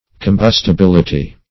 Combustibility \Com*bus`ti*bil"i*ty\, n.